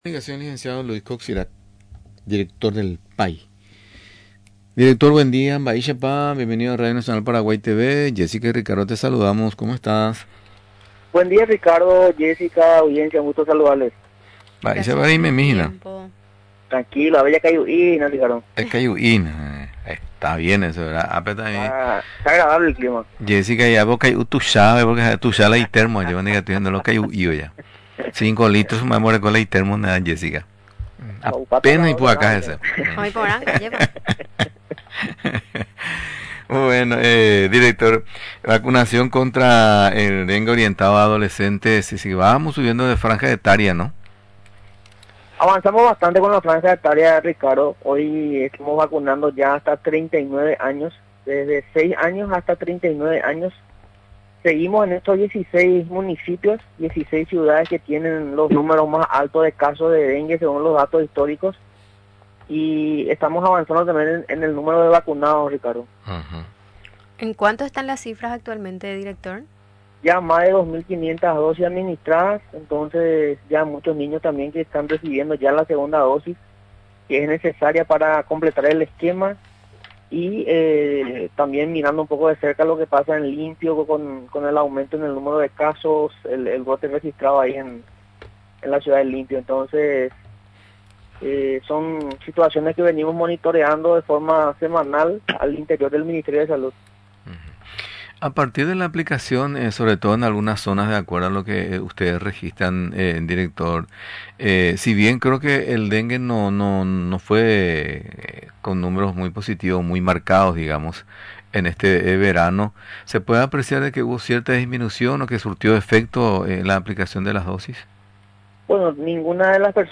Durante la entrevista en Radio Nacional del Paraguay, recordó que el Ministerio de Salud Pública y Bienestar Social, solicita a padres, madres y tutores acudir a los servicios de salud para la vacunación de adolescentes contra el dengue.